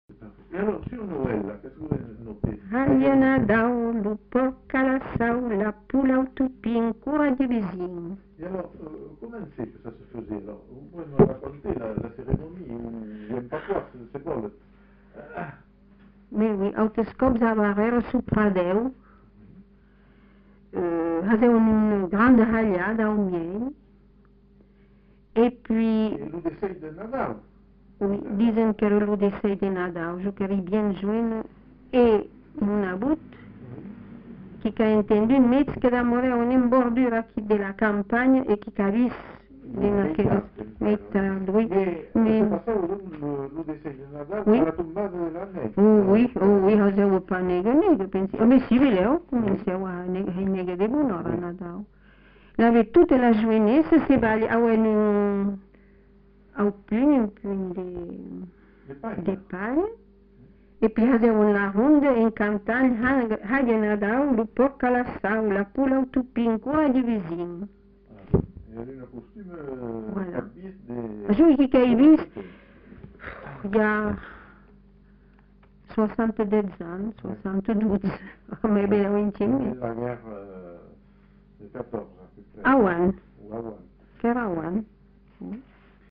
Lieu : Captieux
Effectif : 1
Type de voix : voix de femme
Production du son : chanté
Classification : formulette